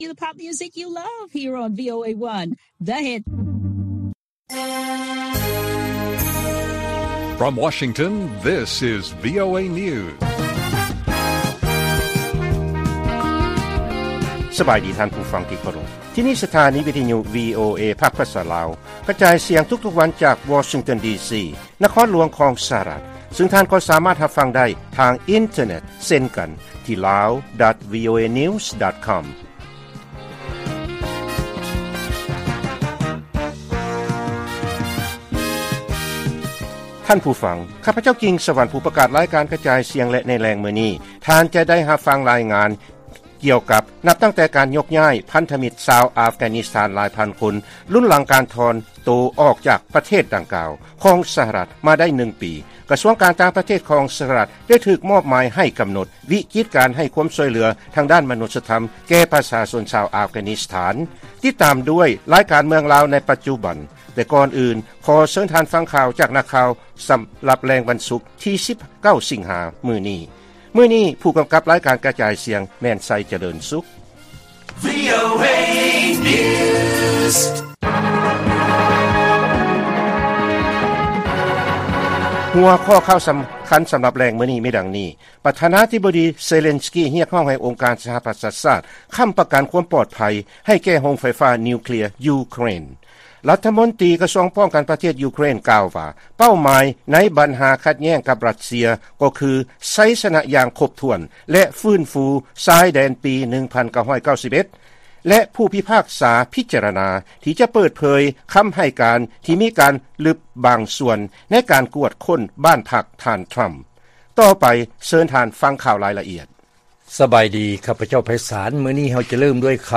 ລາຍການກະຈາຍສຽງຂອງວີໂອເອ ລາວ: ທ່ານ ເຊເລນສກີ ຮຽກຮ້ອງໃຫ້ອົງການສະຫະປະຊາຊາດ ຄ້ຳປະກັນຄວາມປອດໄພ ຕໍ່ໂຮງໄຟຟ້ານິວເຄລຍຢູເຄຣນ